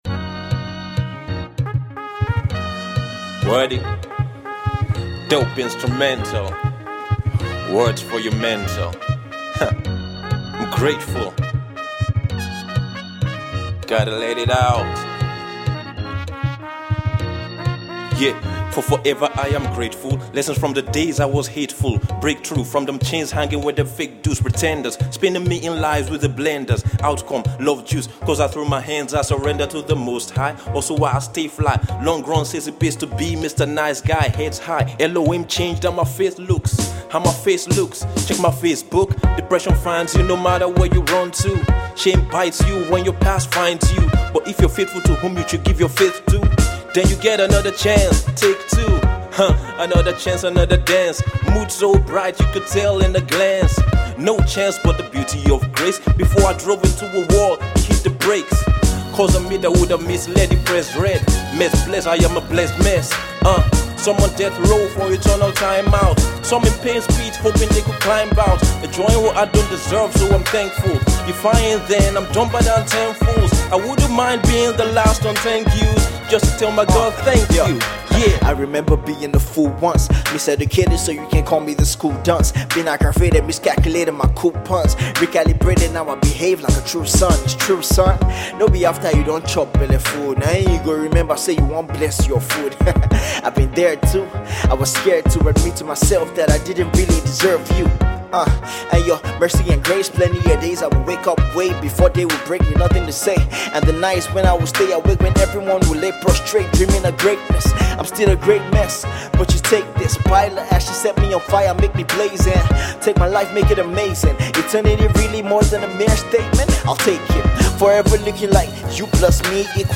Set to the groovy vibe
Hip-Hop
rappers
in a session at Studio 71